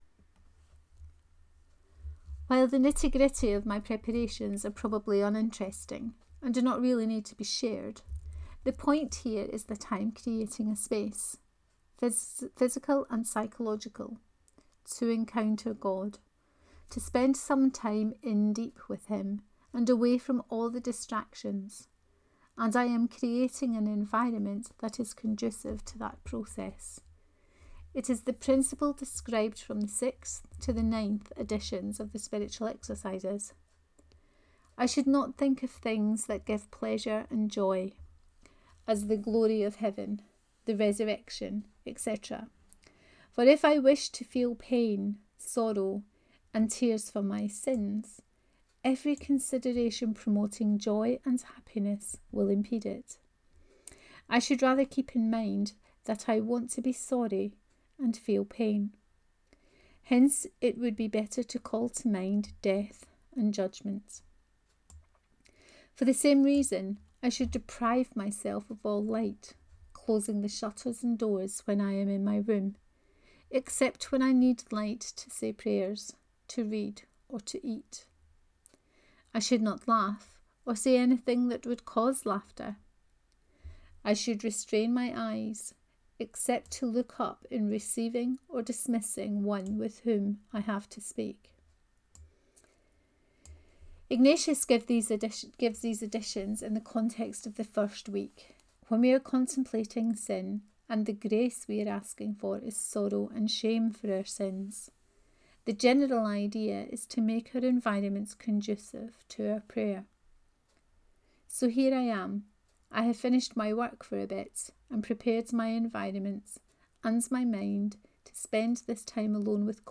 IGR 2020 2: Reading of this post.